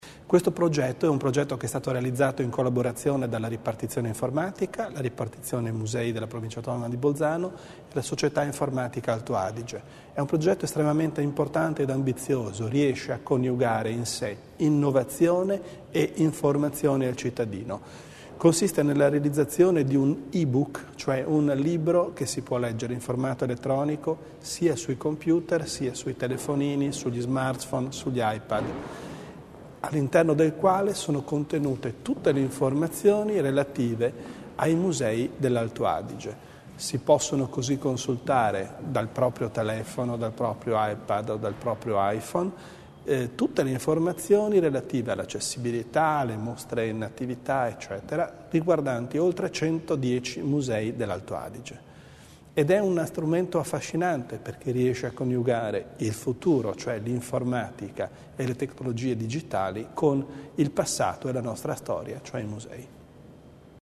L'Assessore Bizzo sui dettagli dell'iniziativa